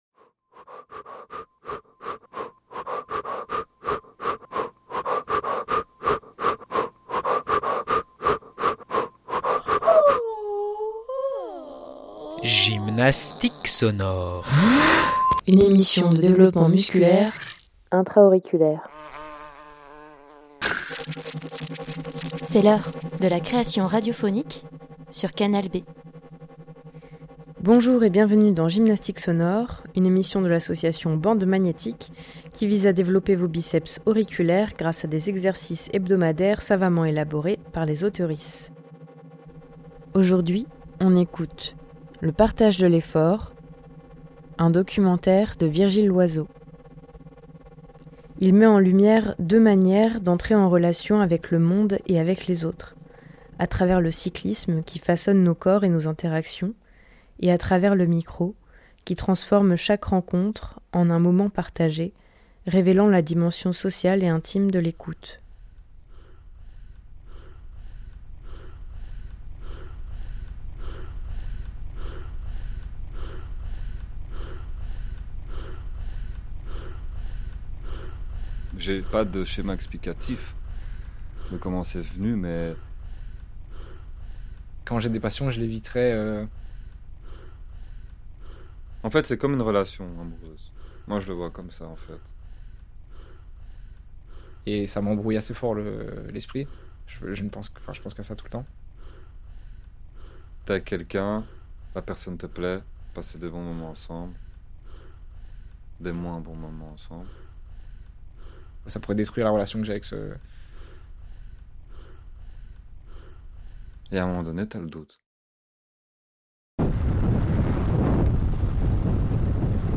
Micro fixé sur mon vélo, je parle tout en pédalant, en laissant surgir mes confidences dans l’effort. On entre alors dans une esthétique de l’intime et de l’intensité, faite de souffle et d’épuisement.
Les entretiens ont été menés dans un cadre intime et amical, ponctués de séquences sonores qui donnent du corps aux récits et font respirer la parole. Ces séquences mêlent des sons enregistrés lors de mes voyages à vélo, souvent en montagne, et des captations réalisées avec des micros posés directement sur le cadre du vélo.